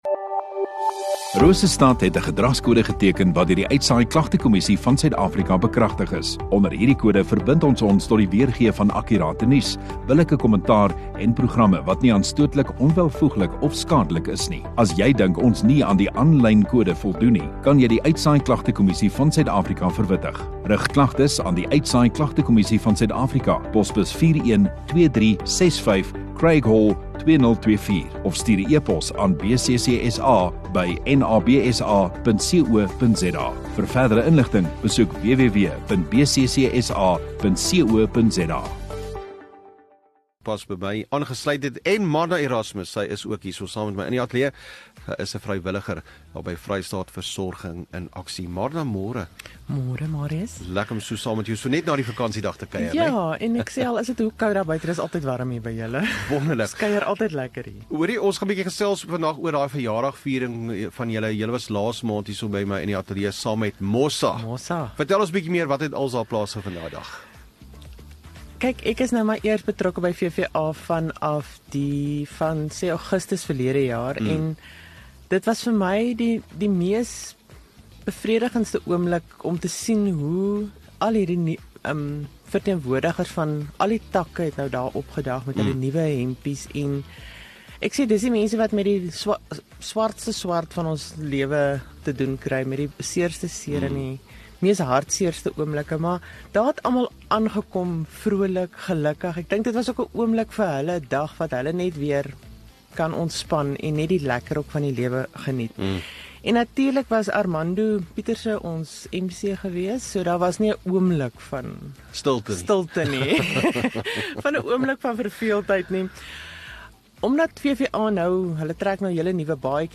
View Promo Continue Radio Rosestad Install Gemeenskap Onderhoude 17 Jun VVA Vrystaat nasorgsentrum